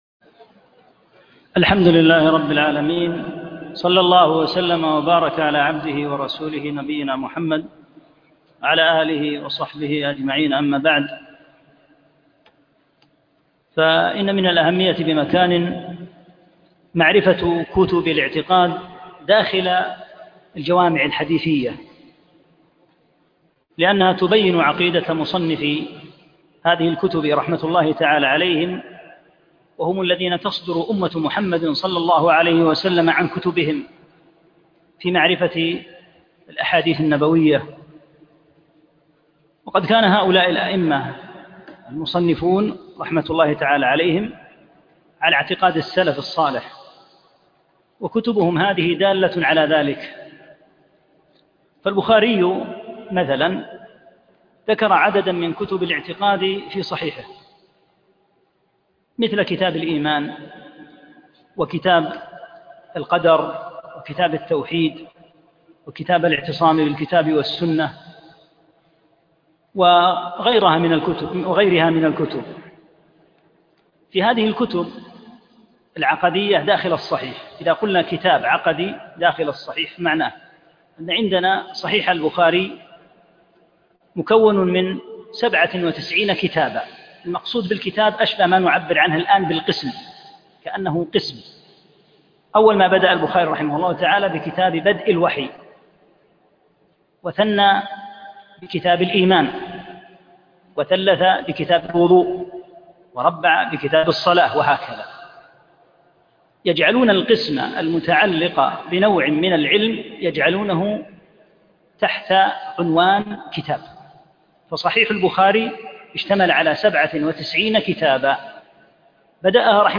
1- الدرس الأول